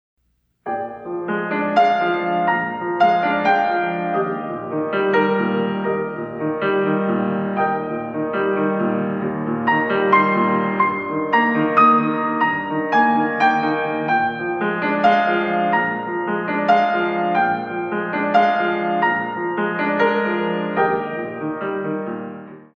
In 3
32 Counts
Adage